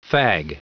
Prononciation du mot fag en anglais (fichier audio)
Prononciation du mot : fag